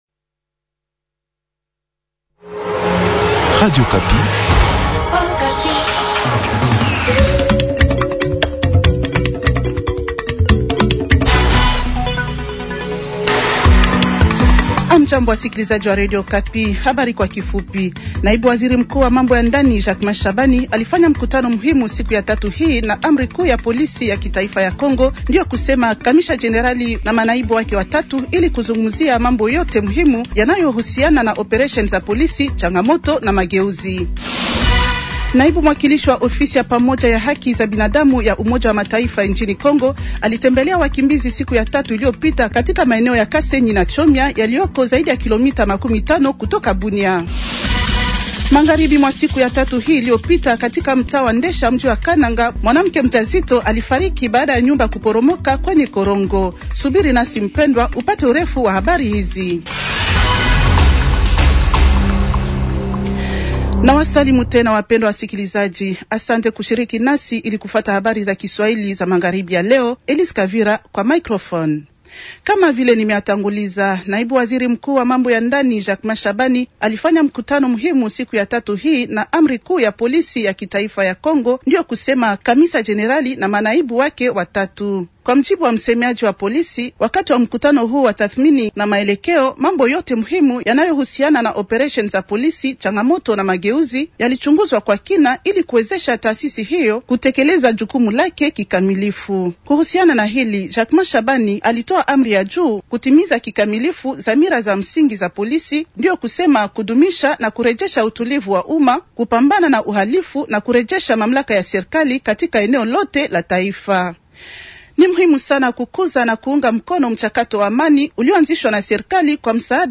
Journal Swahili